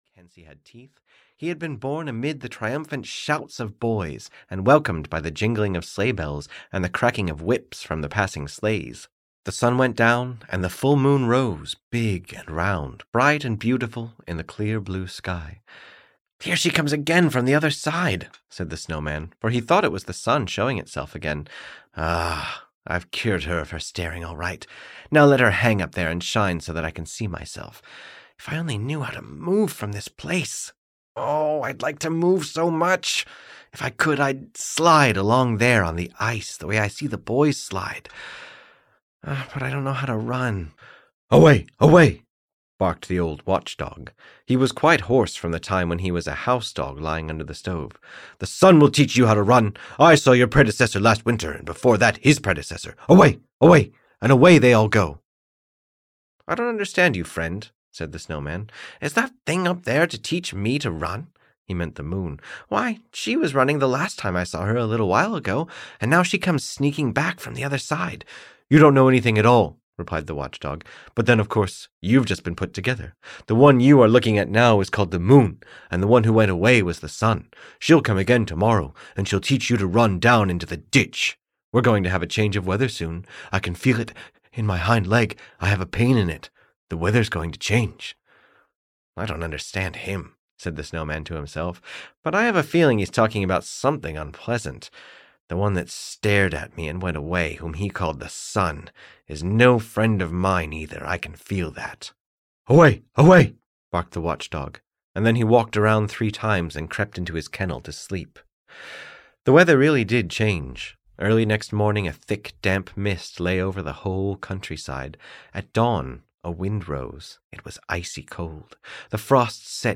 The Snow Man (EN) audiokniha
Ukázka z knihy